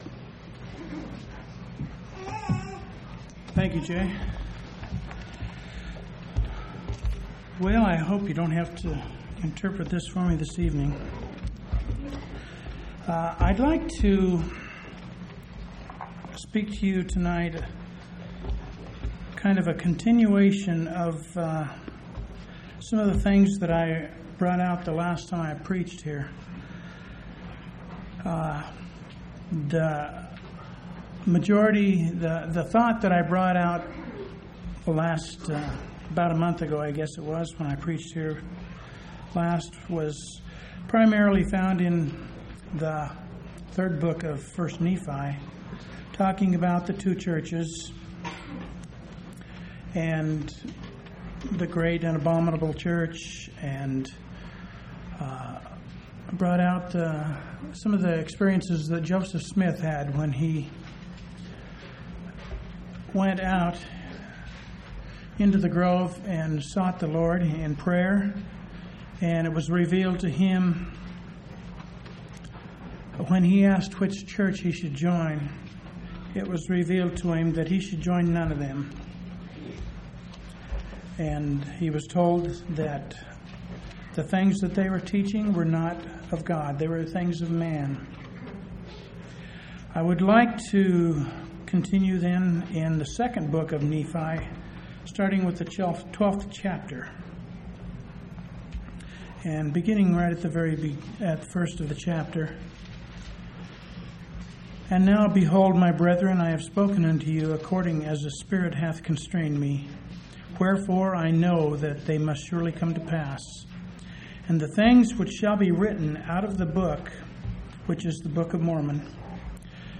11/22/1998 Location: Phoenix Local Event